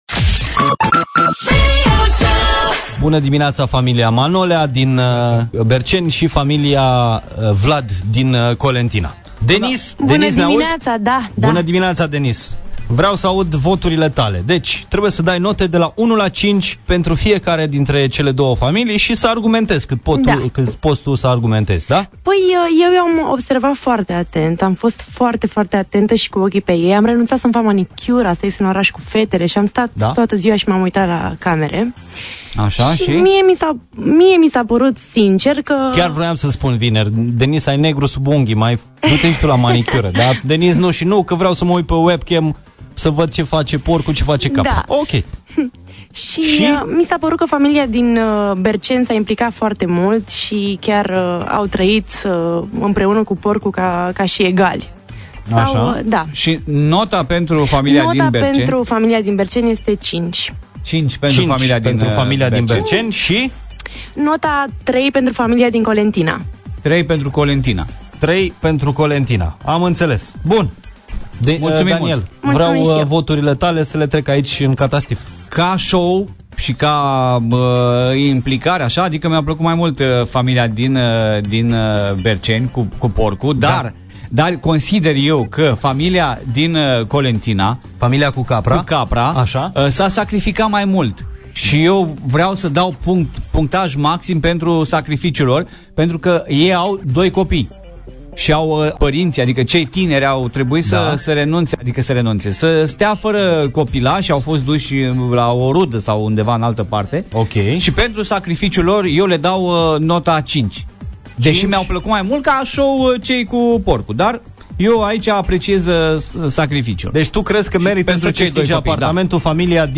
Azi a fost editie speciala, cu transmisie live din apartamentul oferit ca premiu.